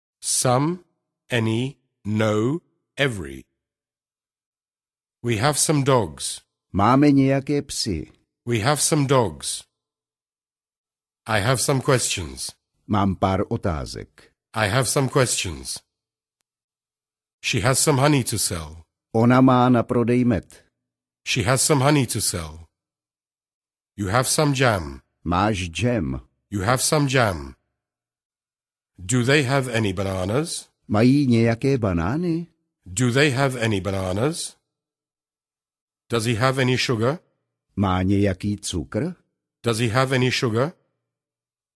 Každou větu uslyšíte anglicky, pak česky a znovu v originálním znění.
Ukázka z knihy
Místo vysvětlování teorie jsme použili gramatiku ve větách namluvených příjemným hlasem rodilého Angličana.